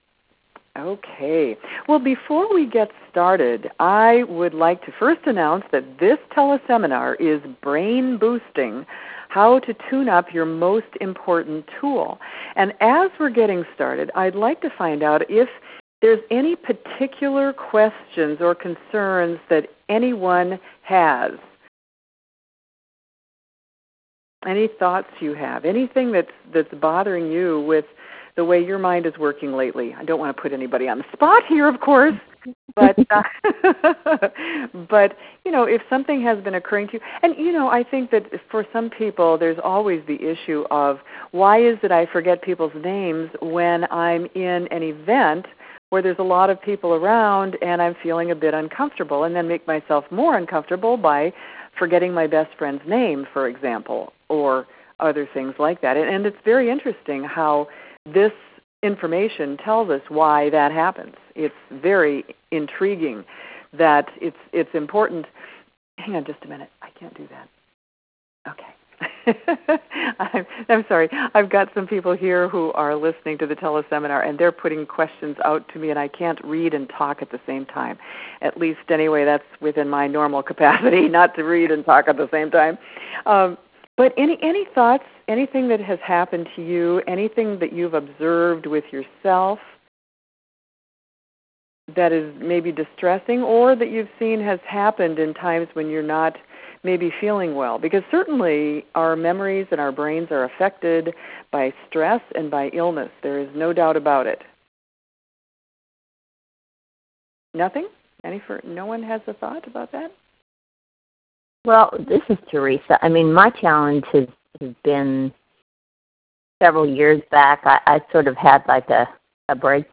A Tele-Seminar